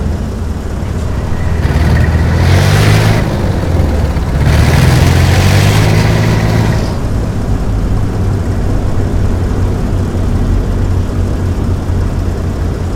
tank-engine-3.ogg